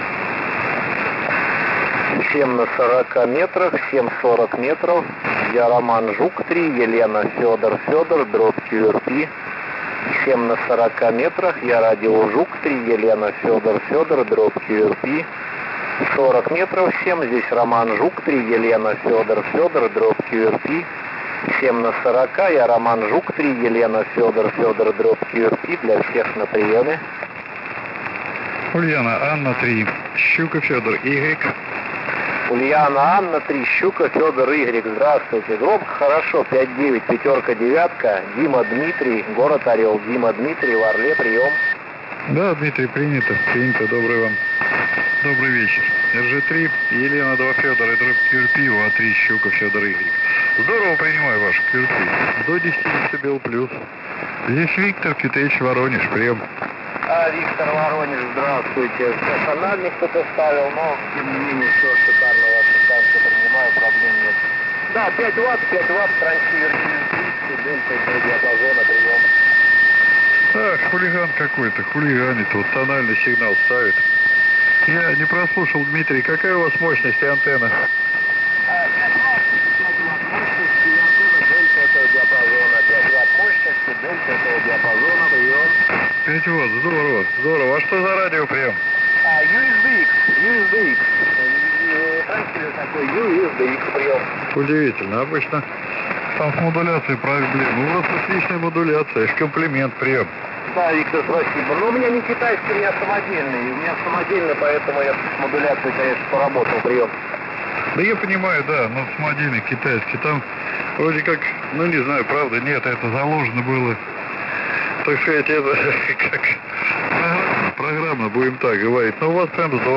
Вот как звучит uSDX с разогнанной сишкой. Хрипы никуда не делись. Но, сигнал поярче.